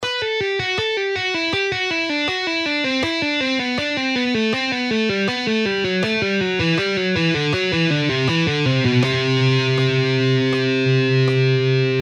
Cascading style guitar licks
Lesson 1: Legato Exercise In Cascade – Lower Harmony
Half Speed:
5.-Legato-Exercise-In-Cascade-Lower-Harmony-Half-Speed.mp3